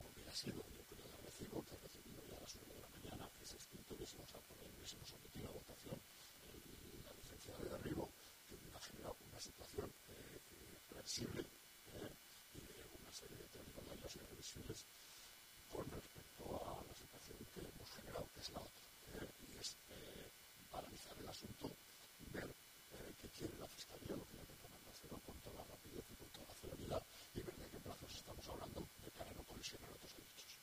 Así lo explica el Consejero de Urbanismo, Carlos Pérez